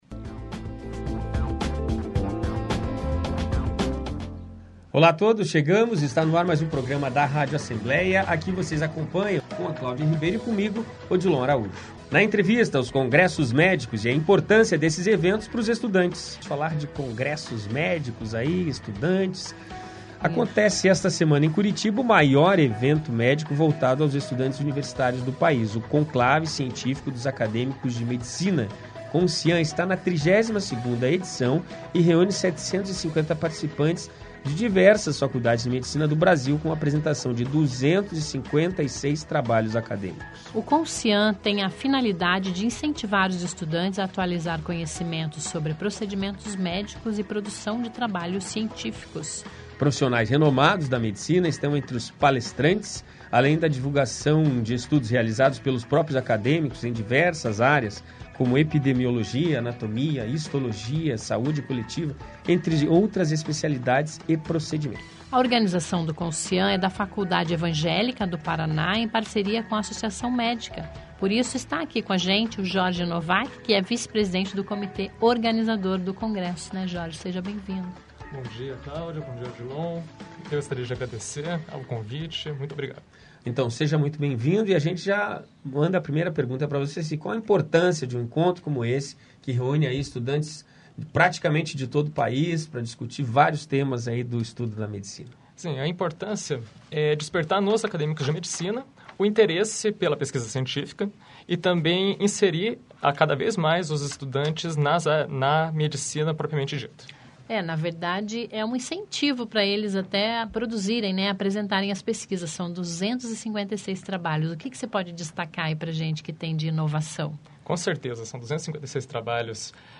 Conversamos com o estudante de medicina e também um dos organizadores de um congresso para estudantes